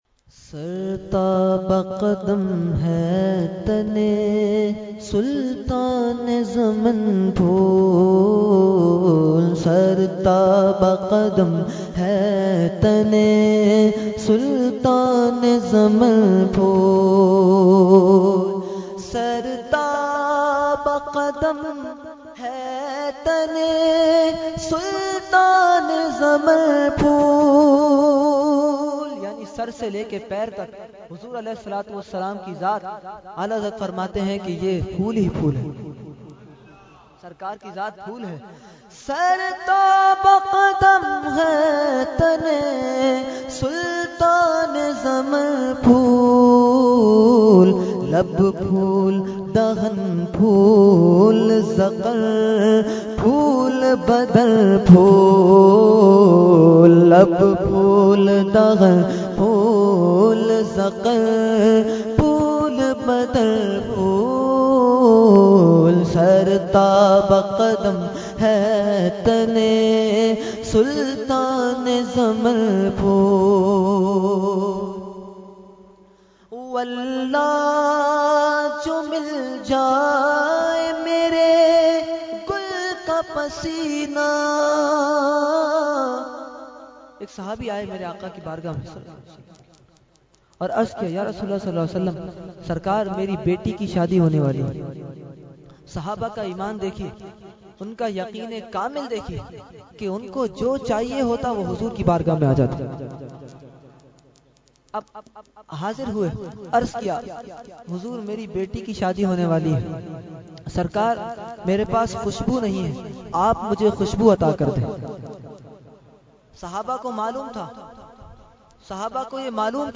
Category : Naat | Language : UrduEvent : Shab e Baraat 2016